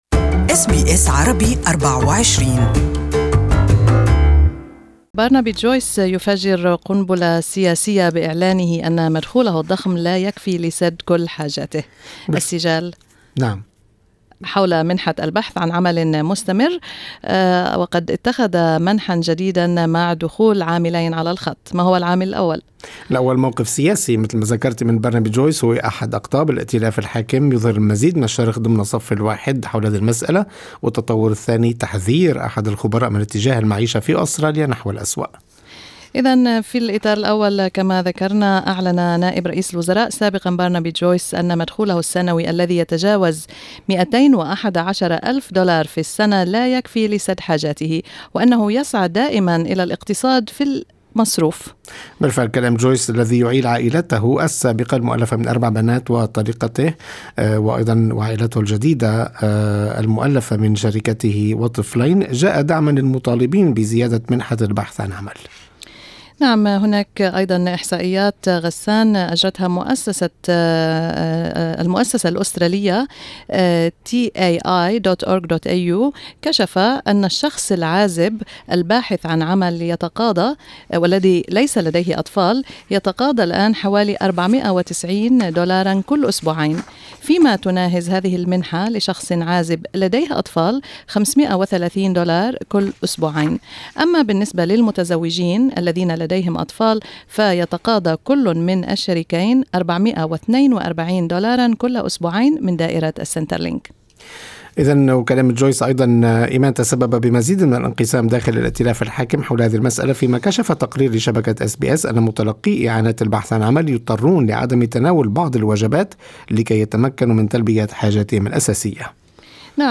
استمعوا إل اللقاء الكامل معه عبر الرابط الصوتي أعلاه.